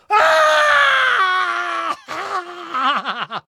fire_scream3.ogg